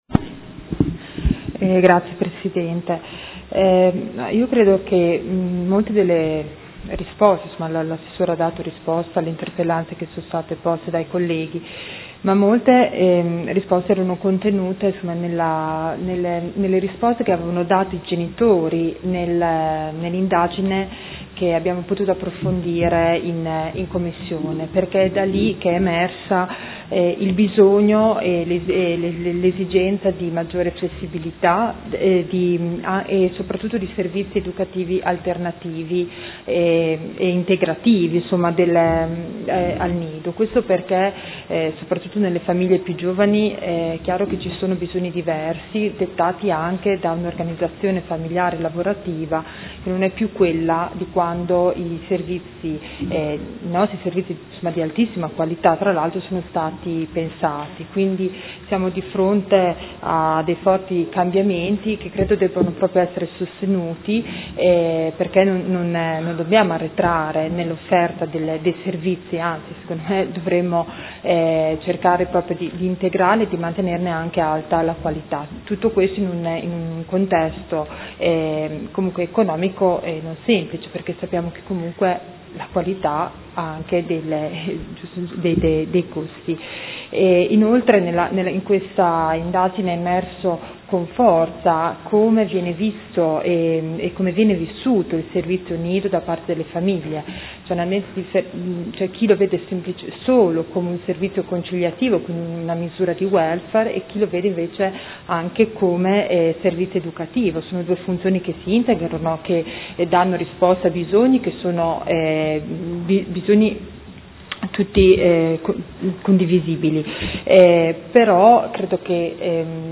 Seduta del 14/04/2015 Dibattito. Interrogazione del Consigliere Rocco (FaS-S.I.) avente per oggetto: Bando comunale servizio nidi e Interrogazione del Gruppo Consiliare Per Me Modena avente per oggetto: Qual è la situazione dei nidi a Modena?